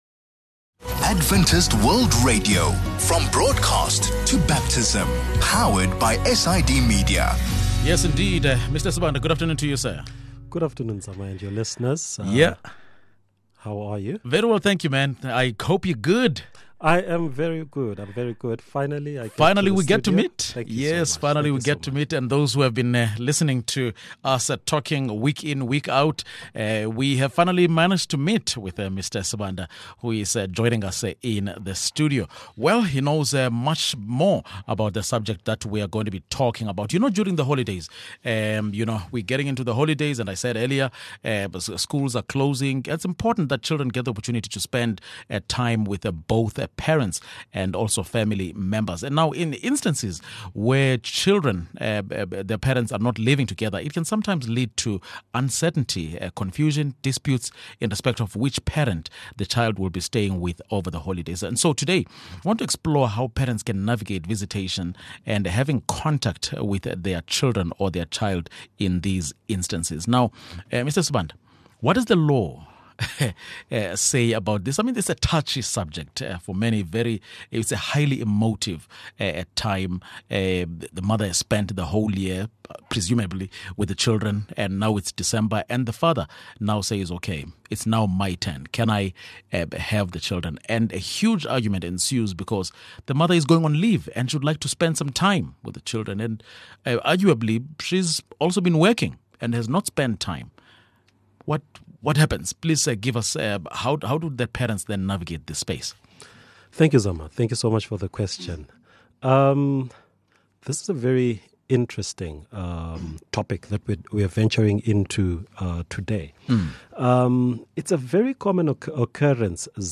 In instances where a child's parents are not living together, it can sometimes lead to uncertainty, confusion or disputes in respect of which parent the child will be staying with over the holidays. In today’s conversation, we will explore how parents can navigate visitation and having contact to their child in these instances.